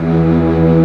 Index of /90_sSampleCDs/Giga Samples Collection/Organ/WurlMorton Brass